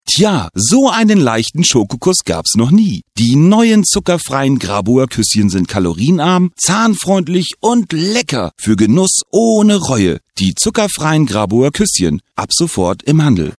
deutscher Stimmenimitator, Sprecher, Entertainer, Musiker..
Sprechprobe: Sonstiges (Muttersprache):